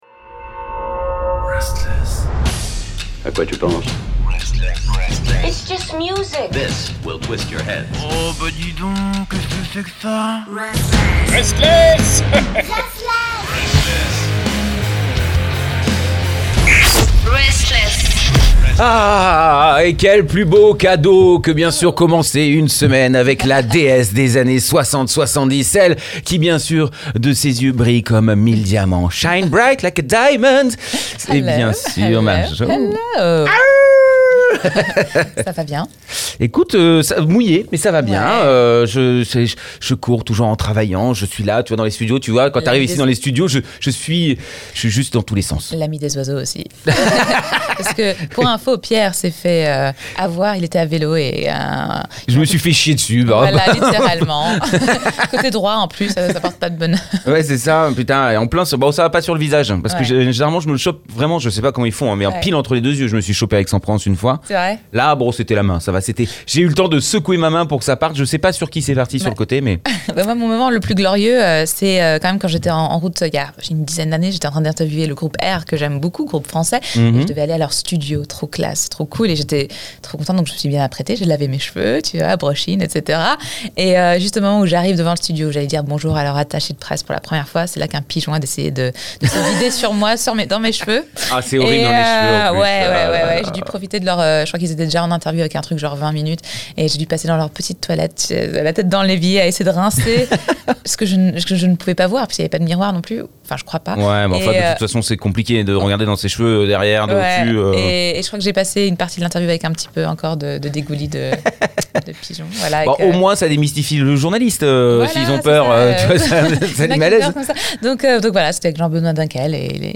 Rencontre aujourd’hui avec le duo Londonnien Girlhood qui font de la bonne pop 90’s avec une prod un peu fuzzy.